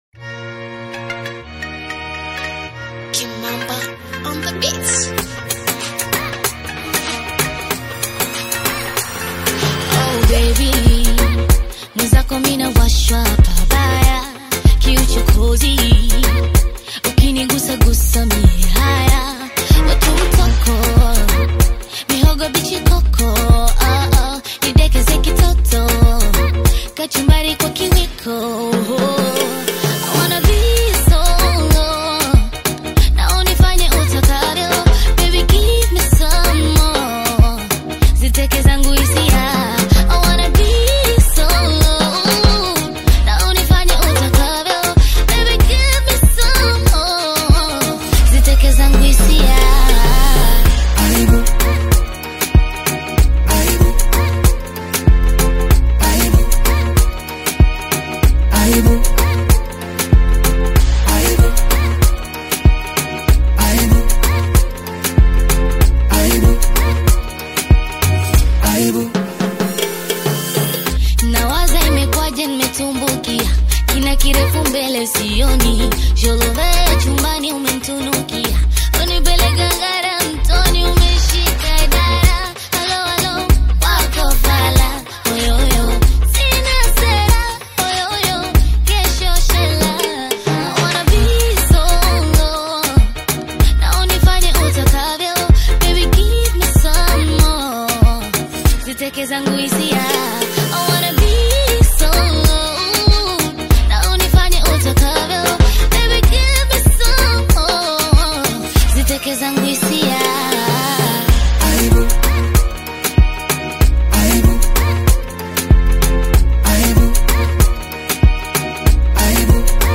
emotive Bongo Flava/Afro-Pop single
Genre: Bongo Flava